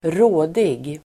Ladda ner uttalet
rådig adjektiv, resolute , resourceful Uttal: [²r'å:dig] Böjningar: rådigt, rådiga Synonymer: fyndig, kvicktänkt, snabbtänkt Definition: kvicktänkt, klok Exempel: ett rådigt ingripande (a resourceful intervention)